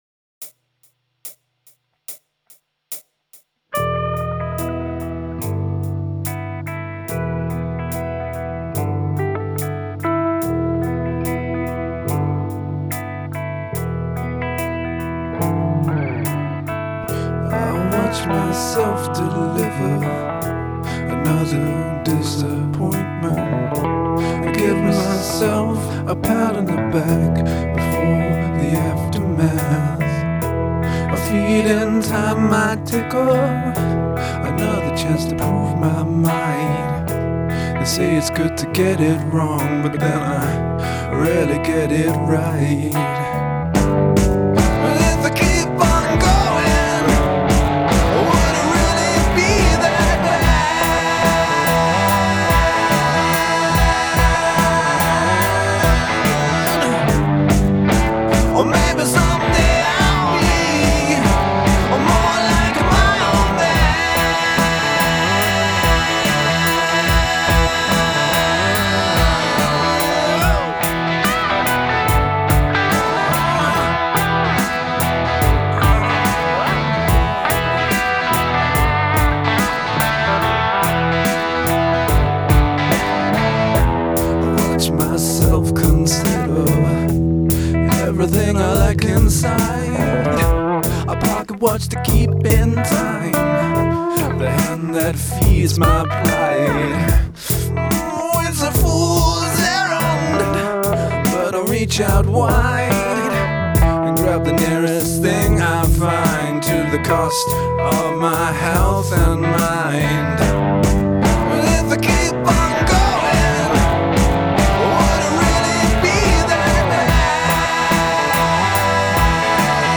I really like how you've used the layered guitar lines to build into the chorus, which really soars at the end with those harmony vocals.
I don’t find the music for this song terribly interesting for me personally, but it’s well performed and a good groove, and the lyrics are much more interesting than last week’s. The first few lines really grab you and I love that you’re leaning into that emotional space.
Your voice fits into this dark country / rock aesthetic, and I would listen to more.
This feels like a classic rock song.
When you sing more quietly, though, particularly at the start, you lose control over your tuning. This is especially clear because of your doubles vocals, which don’t always line up.
The lick in the bass is quite satisfying, and I really like the intro and play out.